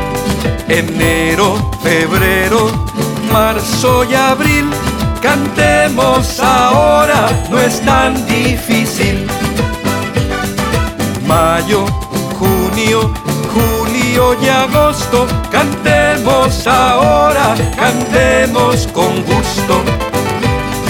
This high-energy Spanish song